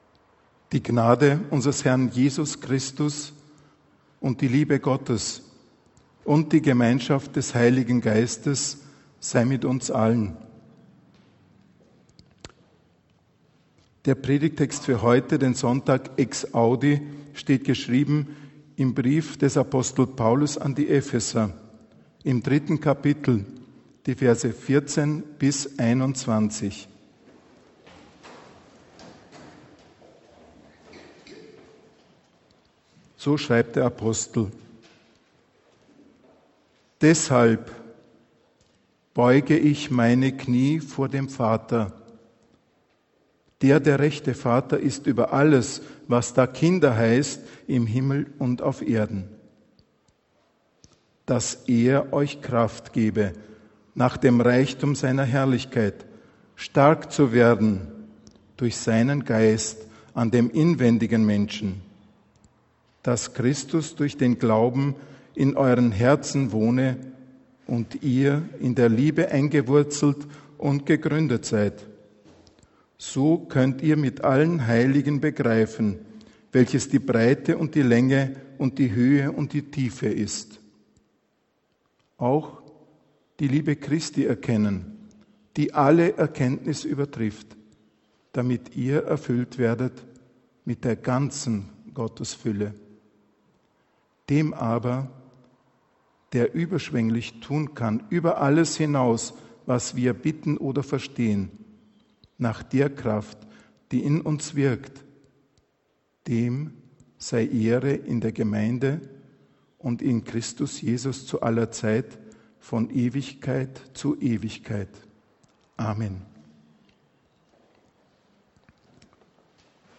Bible Text: Eph 3, 14-21 | Prediger